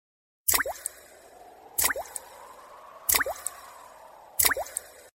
Капли воды